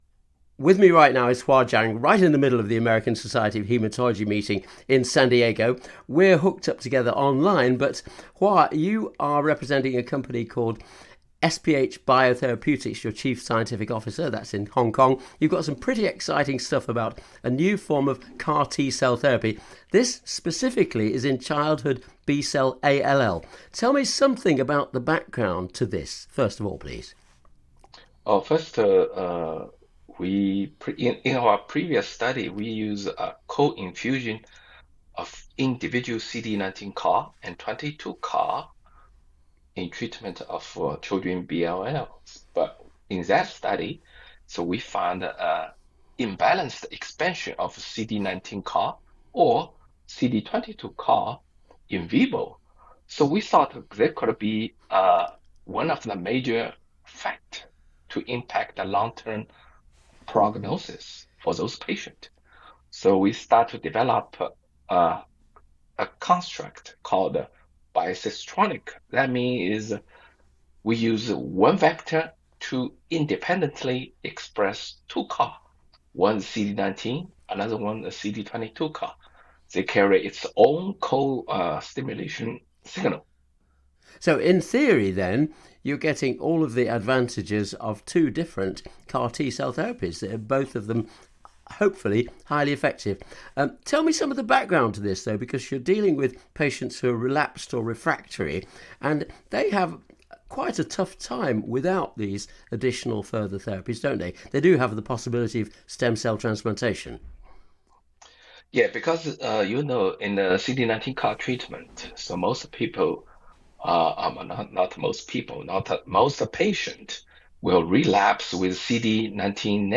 Interviews with the world’s key opinion leaders in cancer